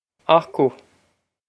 acu a-kuh
This is an approximate phonetic pronunciation of the phrase.